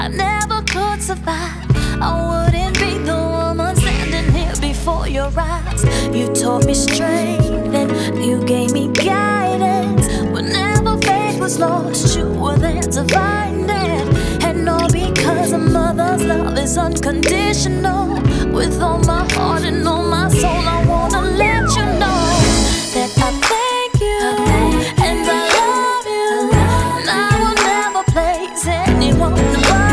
Genre: R&B/Soul